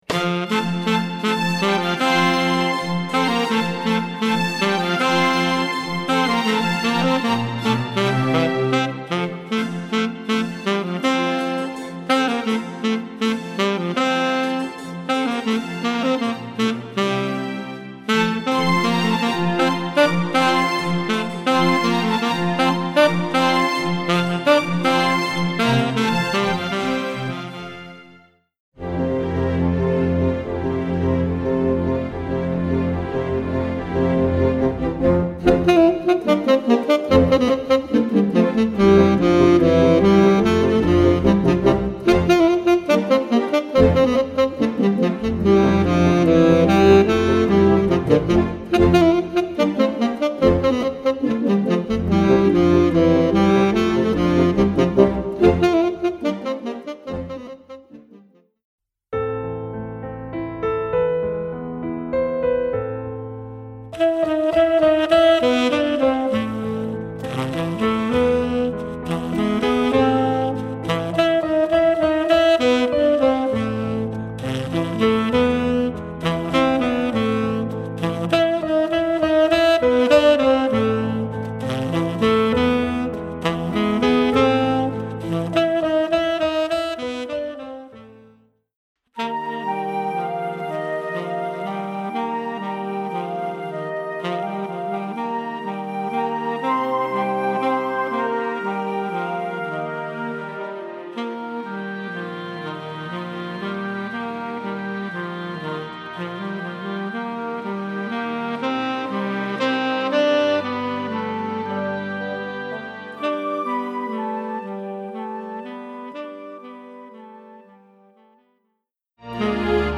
Voicing: T Sax/CD